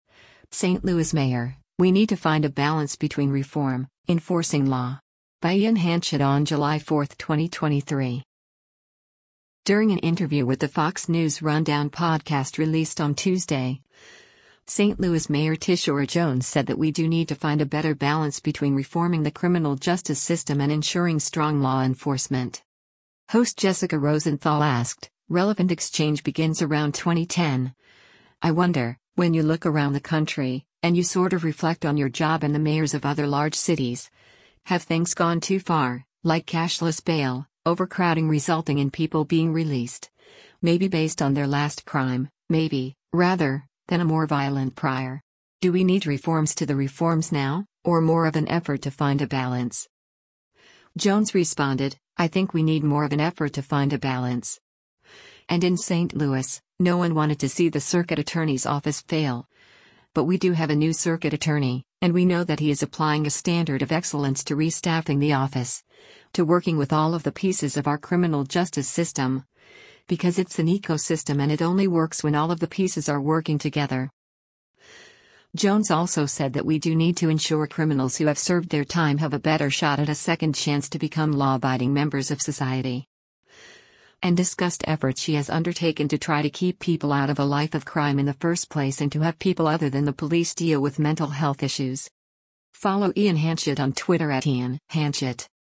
During an interview with the Fox News Rundown podcast released on Tuesday, St. Louis Mayor Tishaura Jones said that we do need to find a better balance between reforming the criminal justice system and ensuring strong law enforcement.